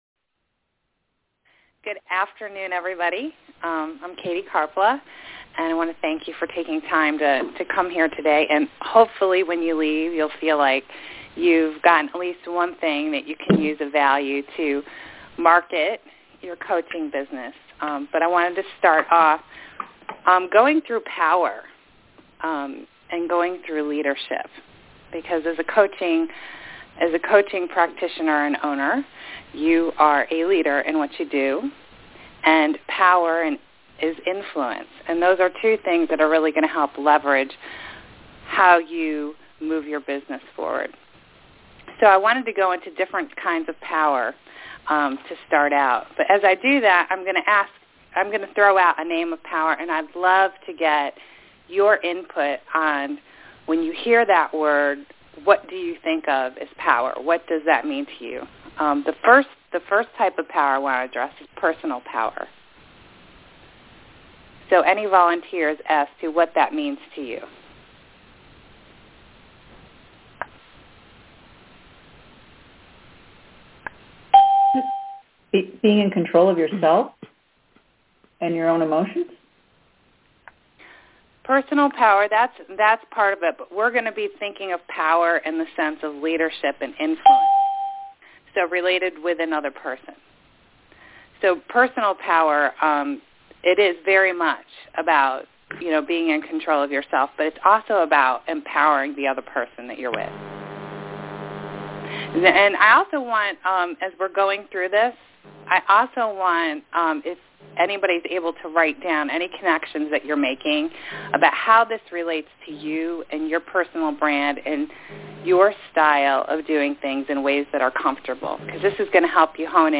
The call will explore basic principles around this subject, and then open up for a creative brainstorming session.